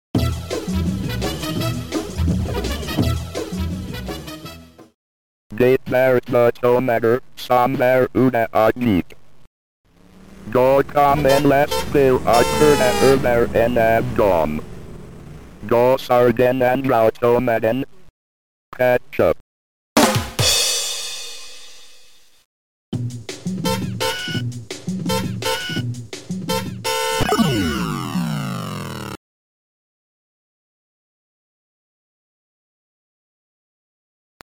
Music ( Noisetracker/Protracker )